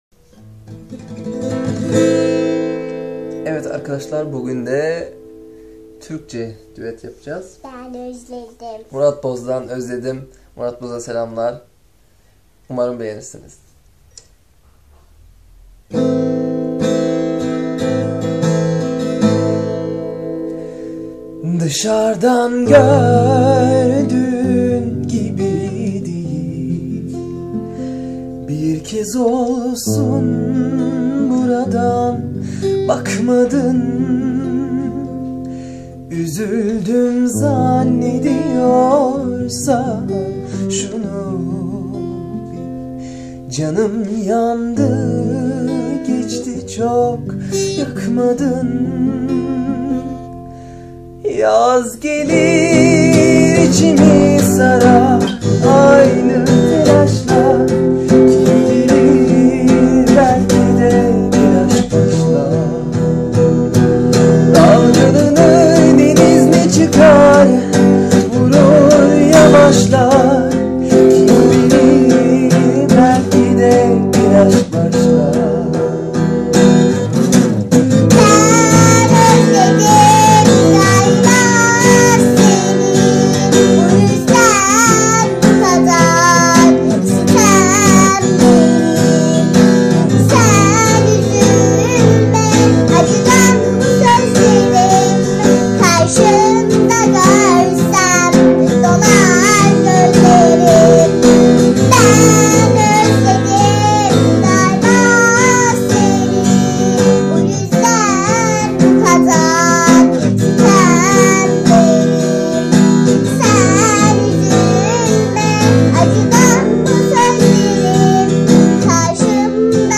با گیتار صدای مرد